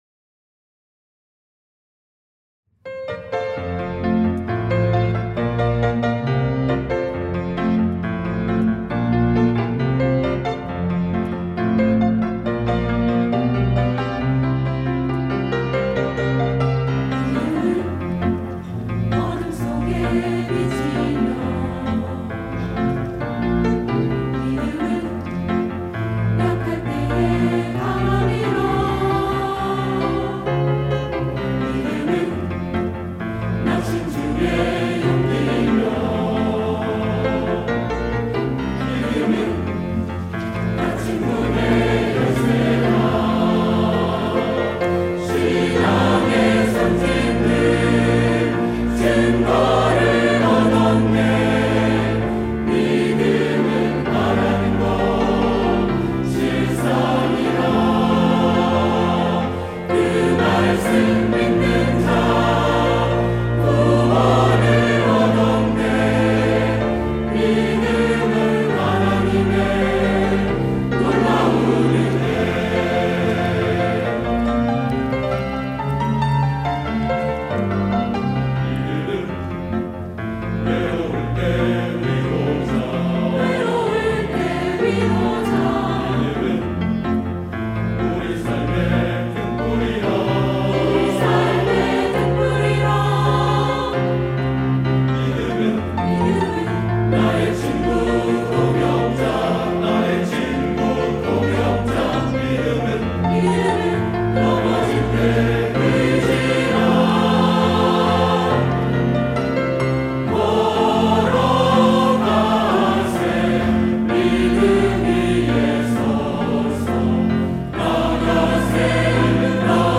할렐루야(주일2부) - 믿음은
찬양대